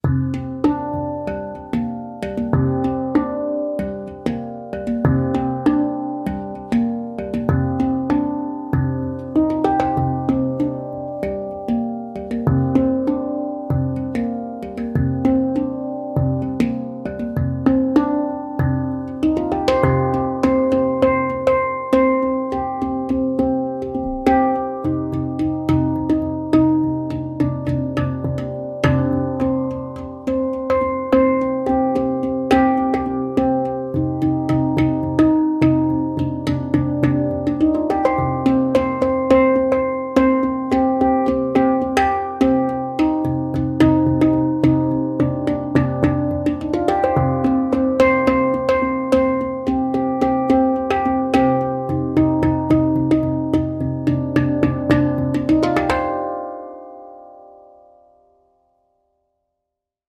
Moon II Handpan i C Low Pygmy (Ø 55 cm) er laget av rustfritt stål og gir en jordnær, mystisk og meditativ klang.
• Stemning: C Low Pygmy – dyp, mystisk og meditativ klang.
• Lang sustain med rike overtoner.
C3, G3, Bb3, C4, D4, D#4, F4, G4, C5